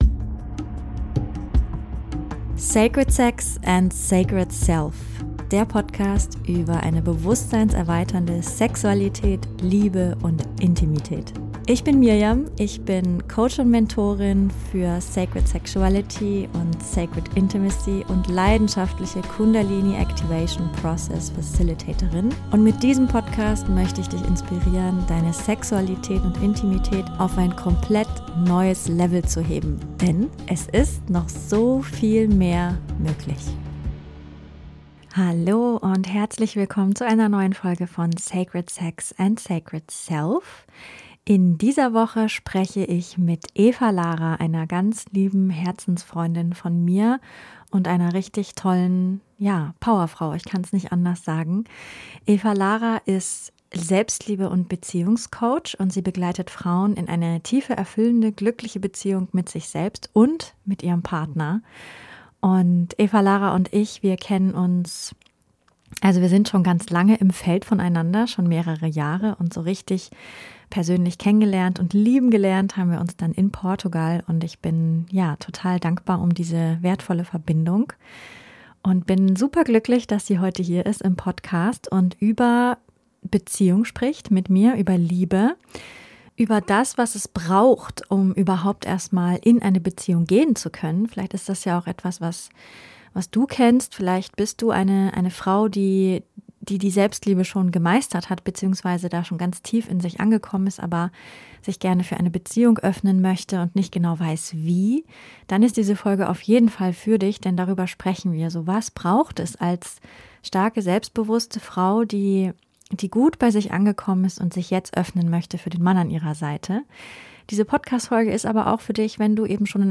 Viel Freude mit diesem Interview!